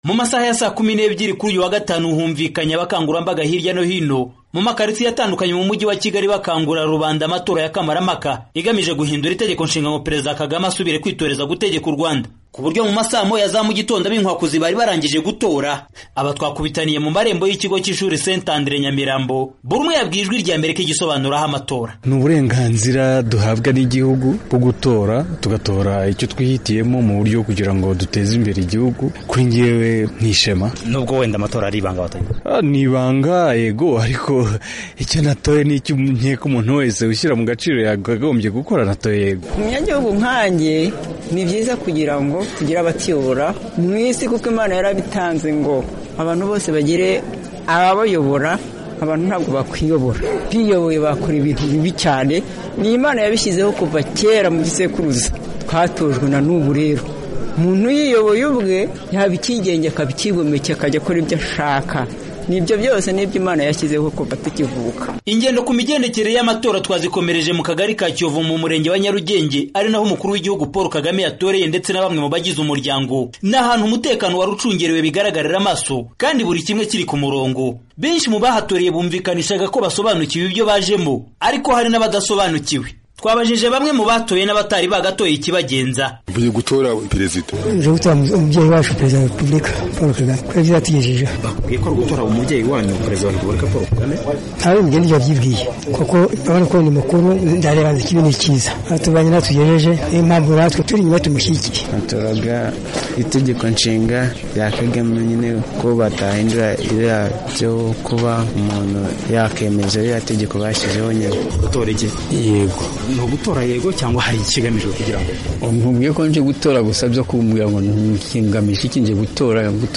yakurikiranye amatora ya Kamarampaka mu mujyi wa Kigali, umurwa w'u Rwanda.